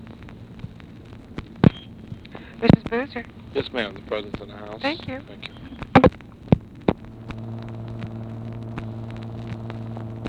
Conversation with UNIDENTIFIED MALE
Secret White House Tapes | Lyndon B. Johnson Presidency